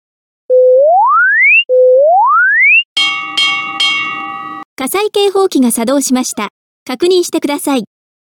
作動時の警報音を日本語以外に、英語、中国語でも音声案内が出来ます。（ただし、日本語と英語、日本語と中国語の組み合わせのみ）